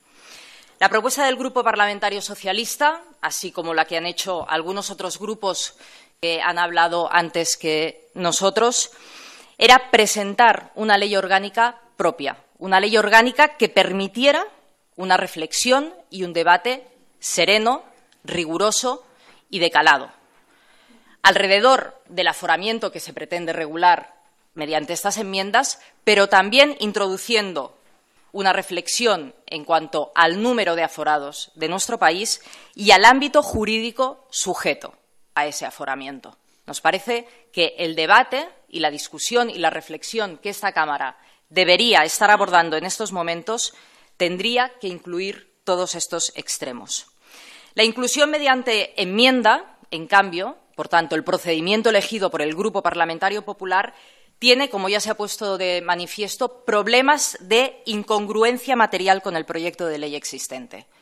Fragmento de la intervención de Meritxel Batet en la Comisión de Justicia. Aforamiento del Rey. 24/06/2014